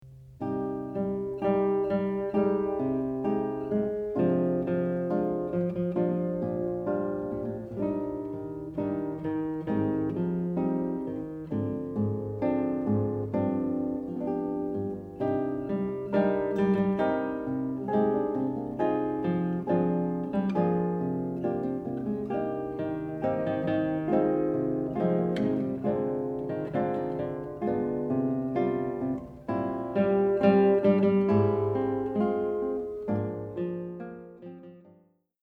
Zwei Gitarren, Crossover
Romantisch-melodische Musik
arrangiert für zwei Gitarren